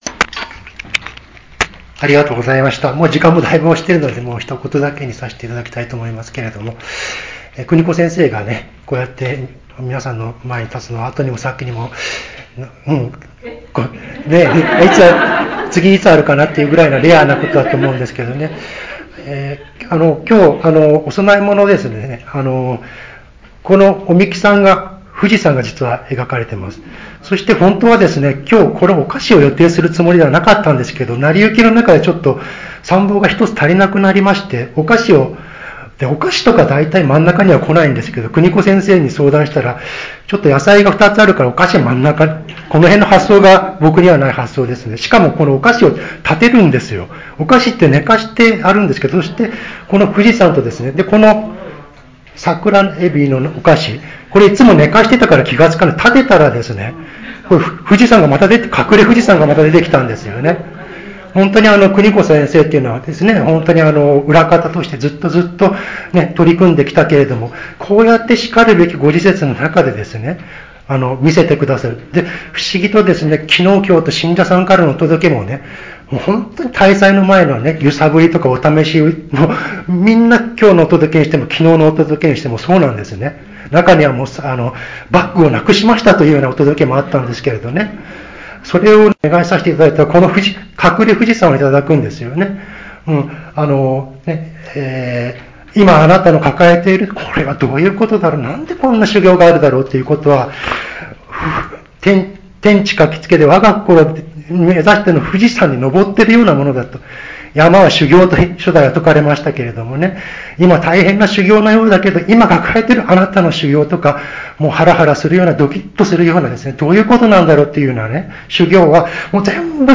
教祖140年生神金光大神大祭